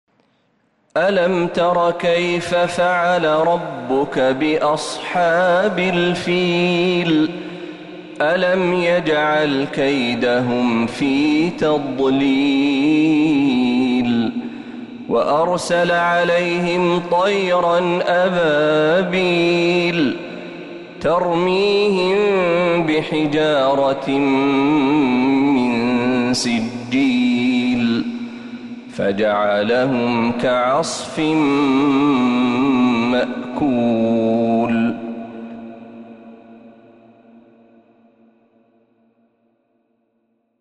سورة الفيل كاملة من الحرم النبوي